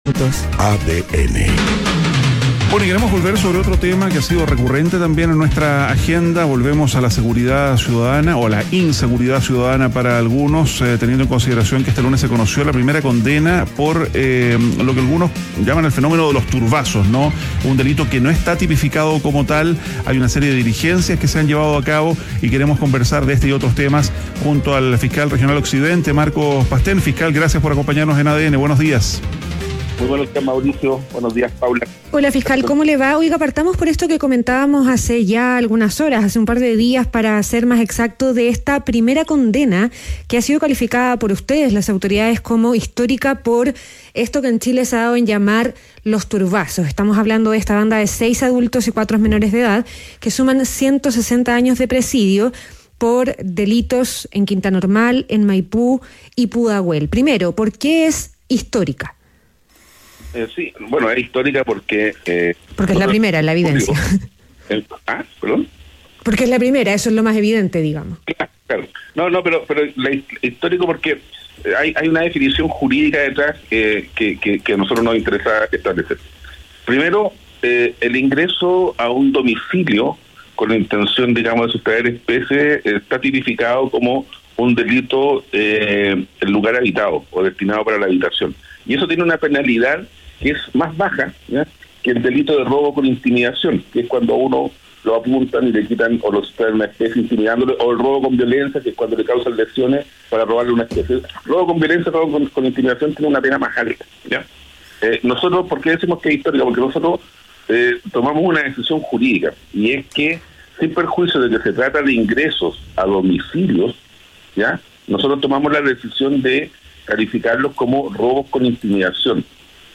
Entrevista a Marcos Pastén, Fiscal Regional Occidente - ADN Hoy